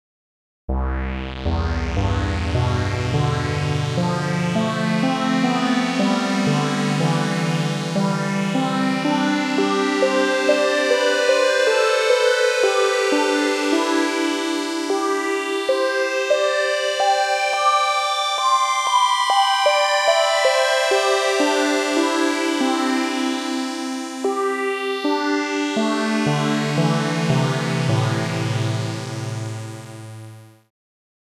当面はSynth1をお手本としてスタンダードな減算式シンセサイザーを作る
16音ポリフォニック オシレーター（正弦波/矩形波） エンベロープフィルター（音量） ピッチベンド エイリアスノイズ除去 Monophonicモード ポルタメント、あとピッチベンドの平滑化 ローパスフィルタ デチューン ディレイ